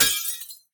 glass5.ogg